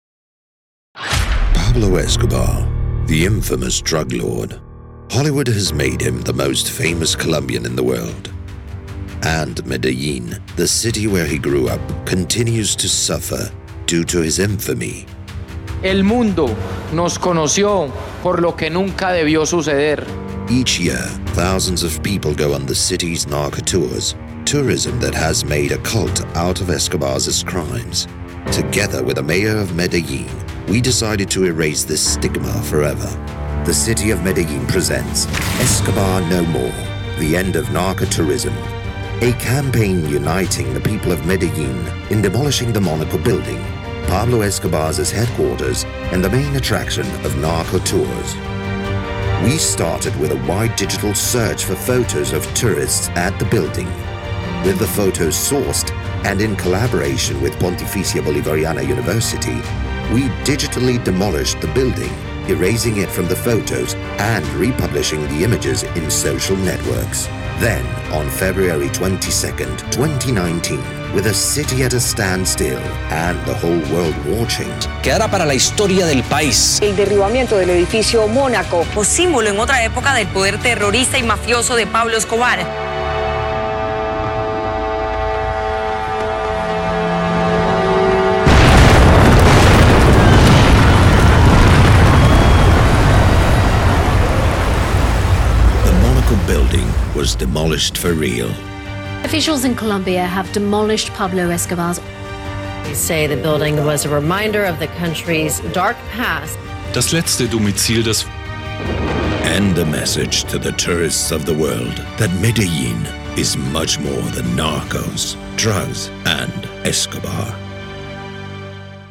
Male
Authoritative, Character, Conversational, Deep, Gravitas, Versatile, Warm